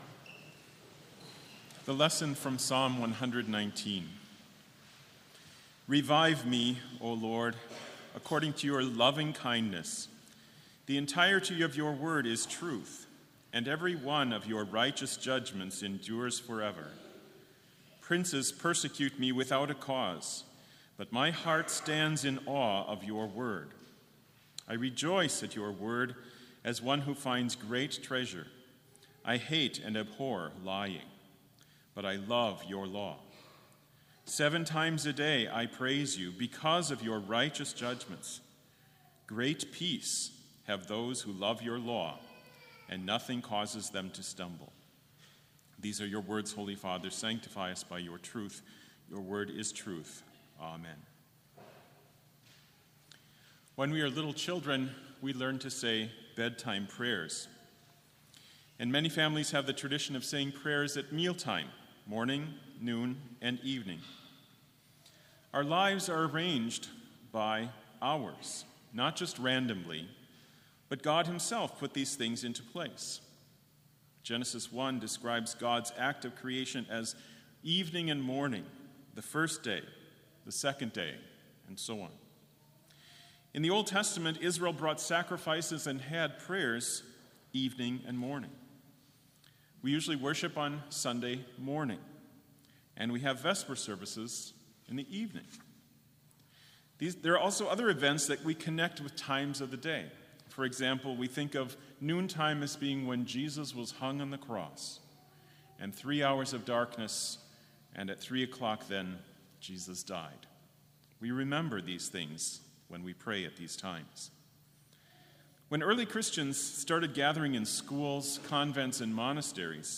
Complete service audio for Chapel - September 10, 2019